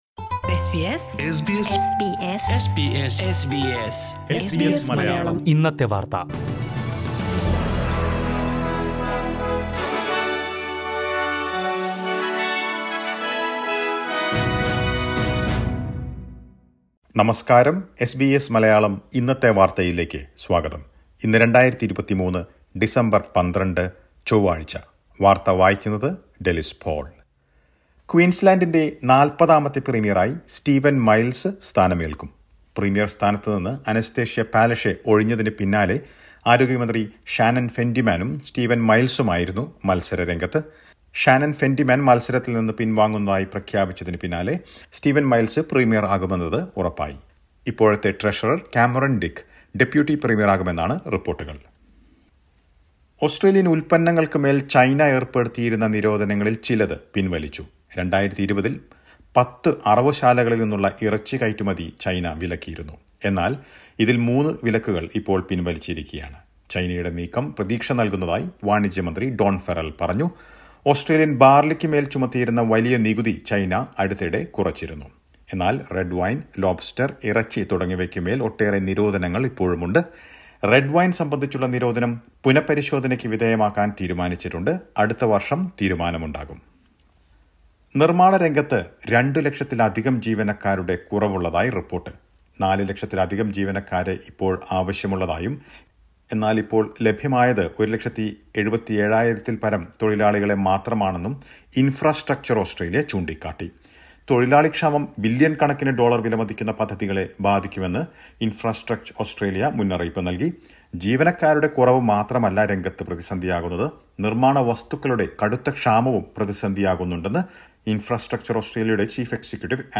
2023 ഡിസംബർ 12ലെ ഓസ്‌ട്രേലിയയിലെ ഏറ്റവും പ്രധാന വാര്‍ത്തകള്‍ കേള്‍ക്കാം...